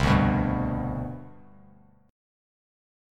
CM7sus2 chord